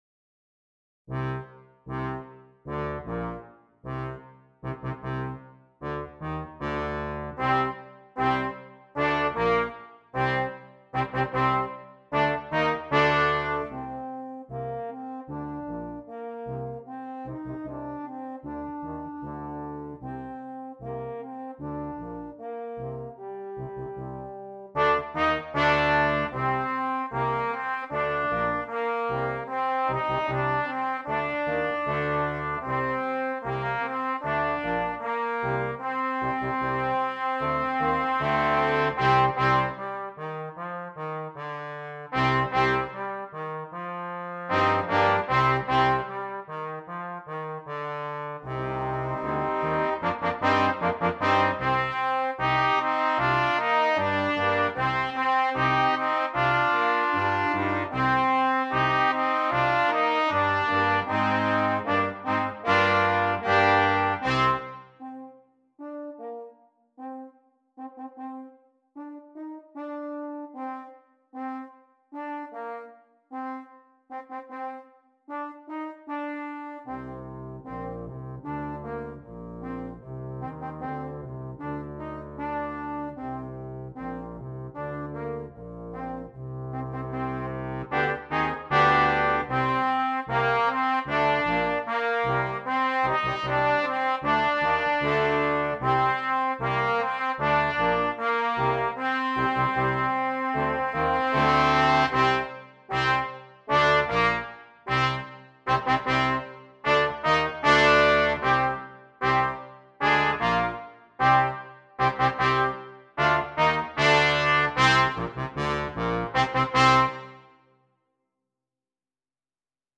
Brass Quintet
This is full of energy and exciting parts for all players.